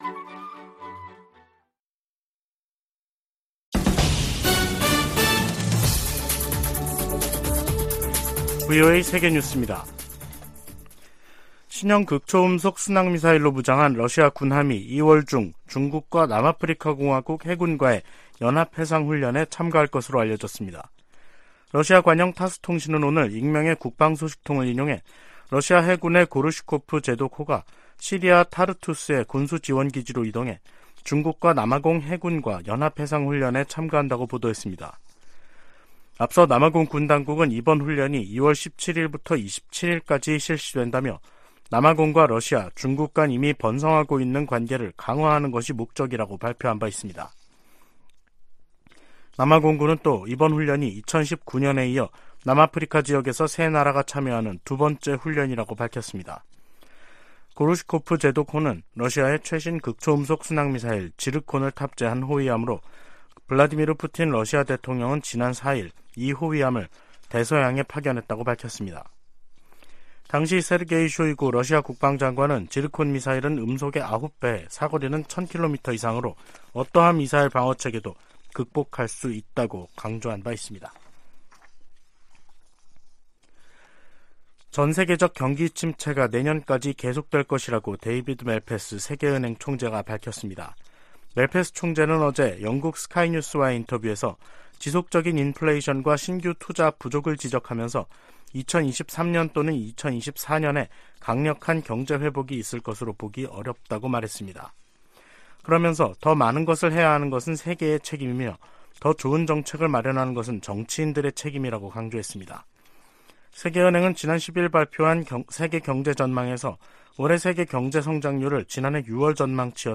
VOA 한국어 간판 뉴스 프로그램 '뉴스 투데이', 2023년 1월 23일 2부 방송입니다. 백악관이 북한과 러시아 용병그룹 간 무기 거래를 중단할 것을 촉구하고, 유엔 안보리 차원의 조치도 모색할 것이라고 밝혔습니다. 미 태평양공군은 한국 공군과 정기적으로 훈련을 하고 있으며, 인도태평양의 모든 동맹, 파트너와 훈련할 새로운 기회를 찾고 있다는 점도 강조했습니다.